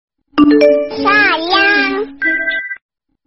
Notifikasi WA Sayang suara anak kecil
Kategori: Nada dering
Keterangan: Dipersembahkan bagi Anda yang menyukai notifikasi WA Sayang suara anak kecil yang menggemaskan.
notifikasi-wa-sayang-suara-anak-kecil-id-www_tiengdong_com.mp3